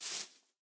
grass2.ogg